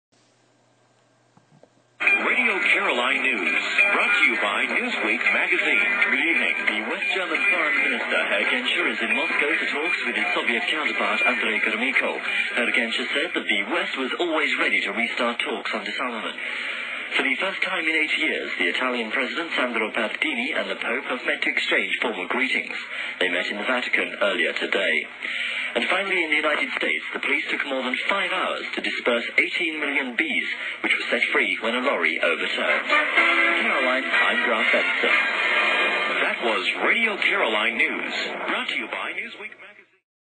In questa breve traccia audio, mi sentite nei primi anni ’80, mentre leggevo il notiziario del giorno al microfono.
In this short audio clip, you can hear me in the early ’80s, reading the news of the day on the air.